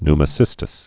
(nmə-sĭstĭs, ny-)